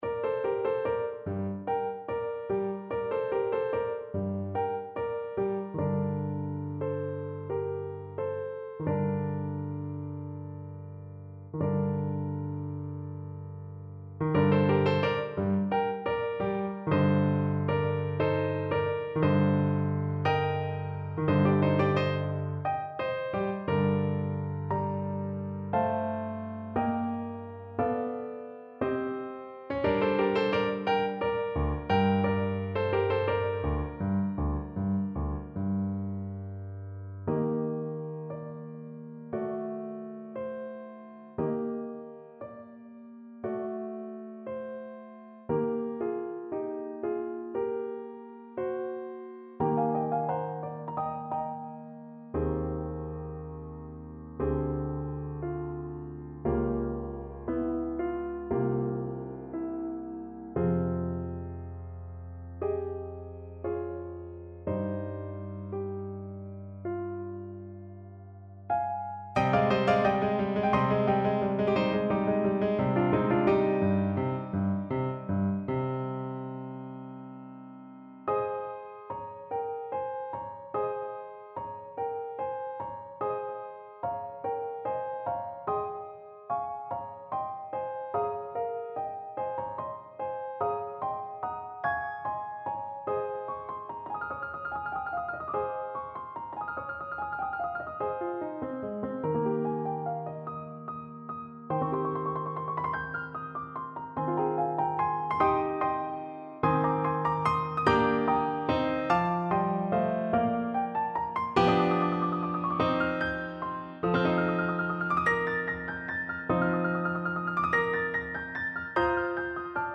Clarinet version
4/8 (View more 4/8 Music)
= 69 = 100 Allegro (View more music marked Allegro)
Classical (View more Classical Clarinet Music)